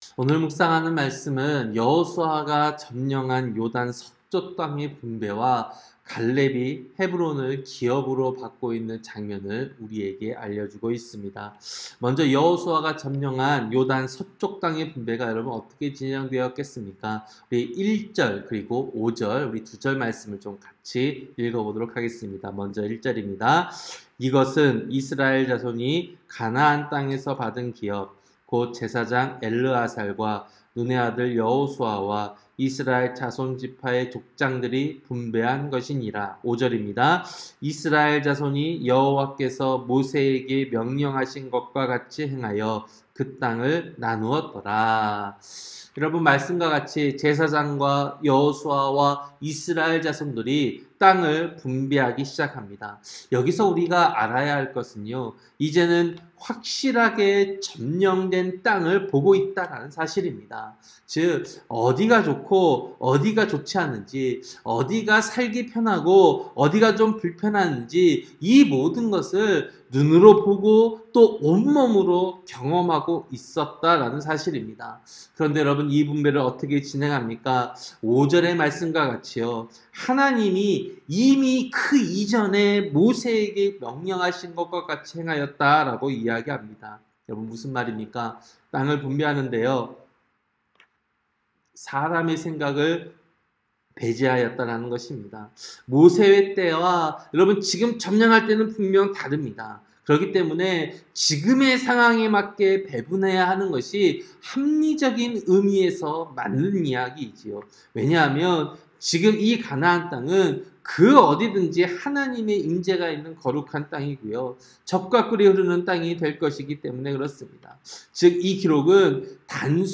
새벽설교-여호수아 14장